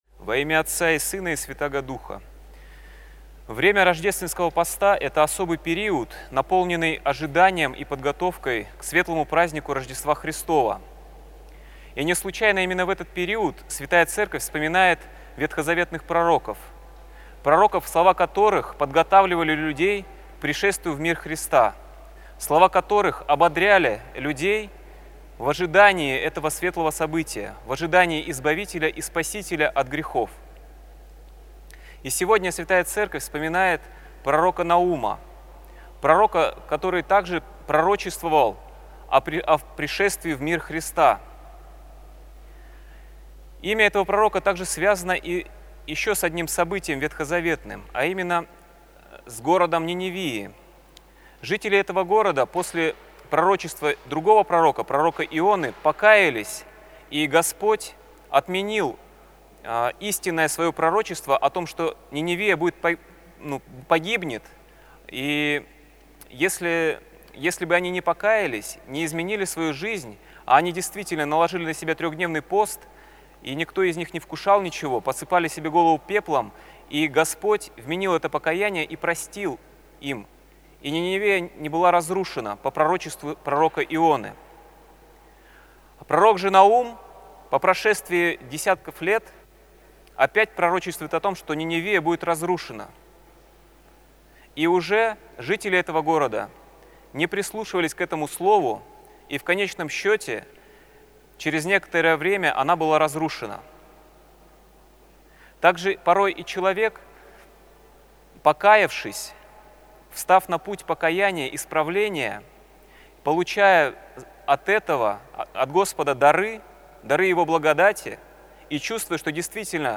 ПРОПОВЕДЬ
Проповедь на Всенощном бдении